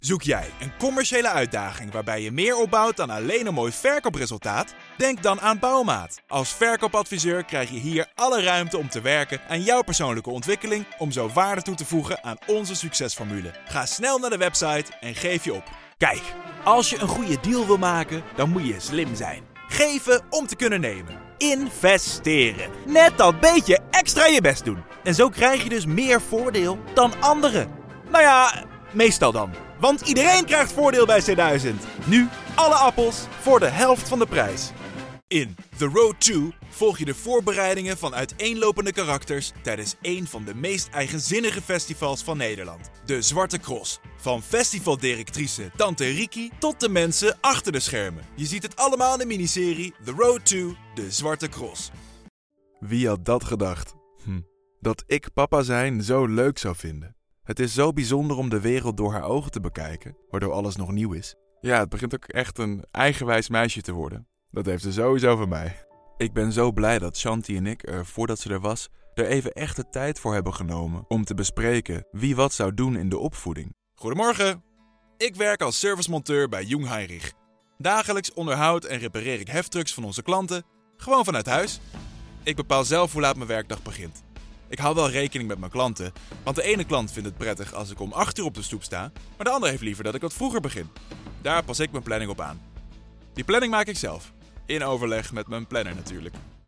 Sprechprobe: Werbung (Muttersprache):
Fluent in both Dutch and English, and with a wide range of voice, from a warm low to an energetic high.